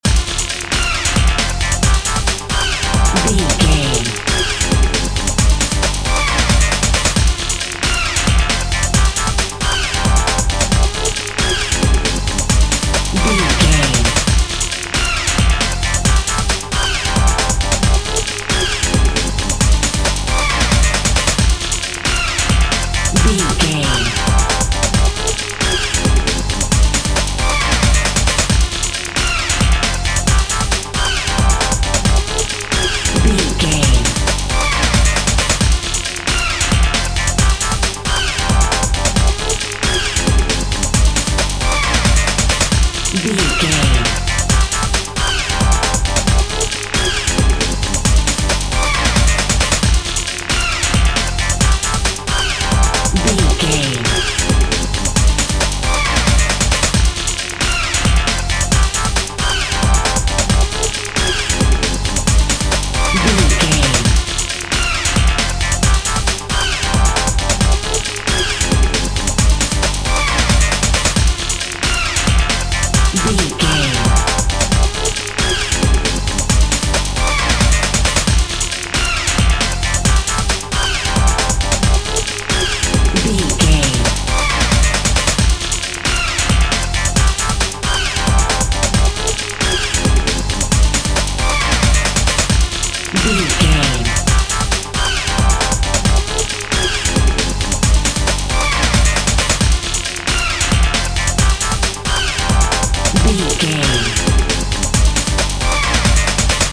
Aeolian/Minor
frantic
energetic
dark
hypnotic
industrial
drum machine
synthesiser
breakbeat
power rock
synth drums
synth leads
synth bass